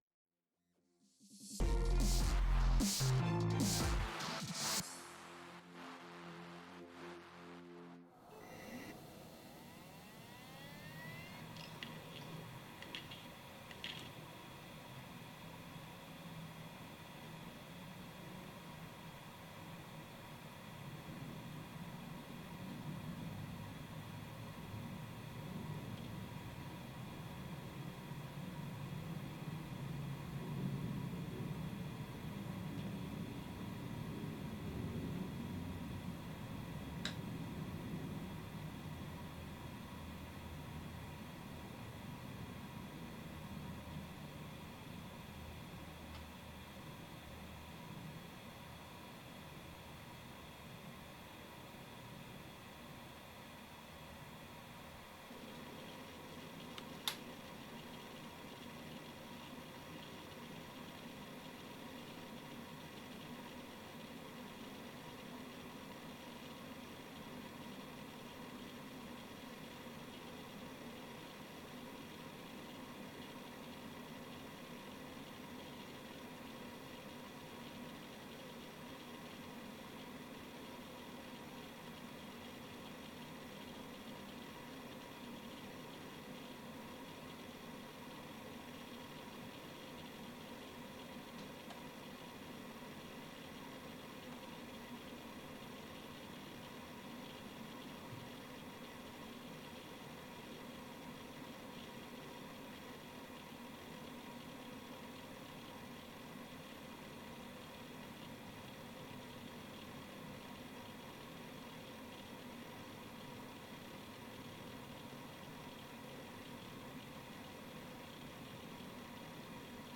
HDD noise levels table/list